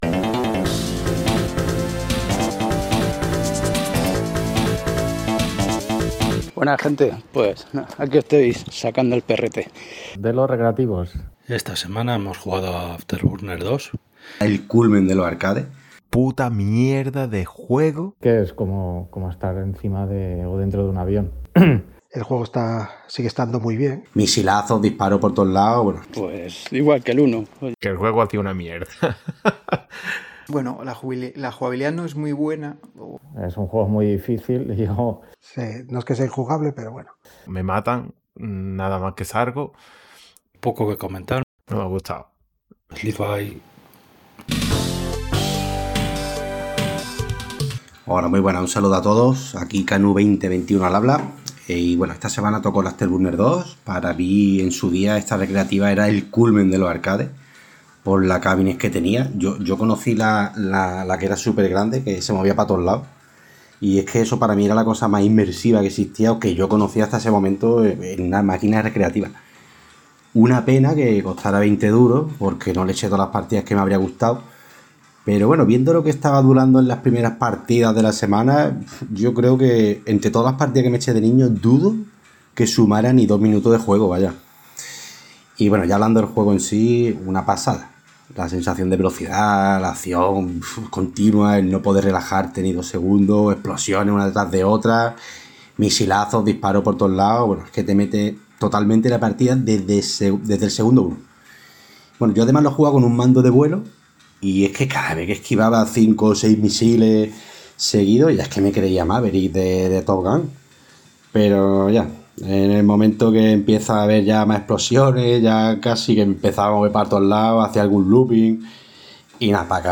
En fin, un buen puñado de audios con las opiniones de nuestros jugadores.